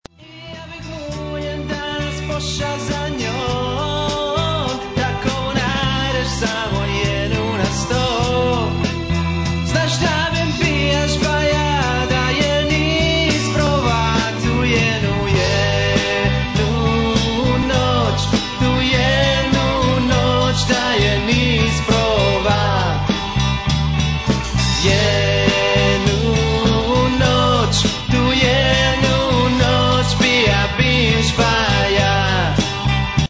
Mixano u "Profile Studios" Vancouver BC
u "Slanina Studios" Vancouver BC...